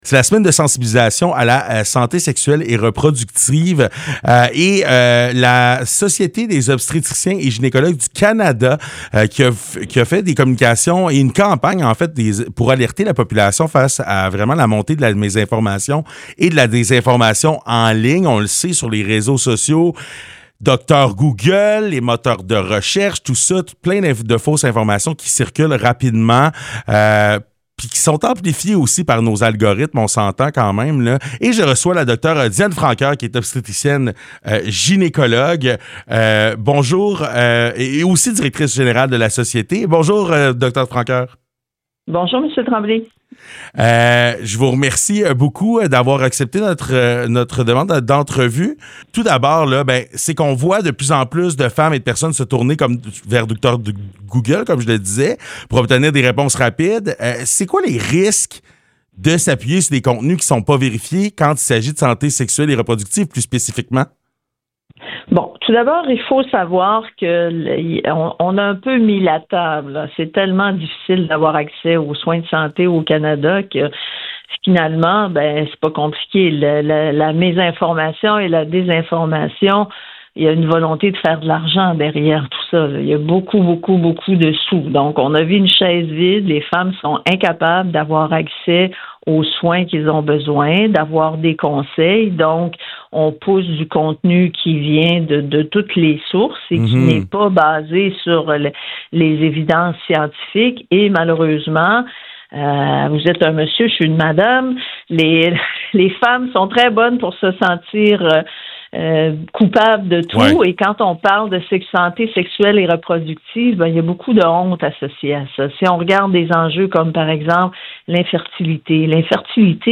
Un entretien extrêmement intéressant